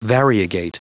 Prononciation du mot variegate en anglais (fichier audio)
Prononciation du mot : variegate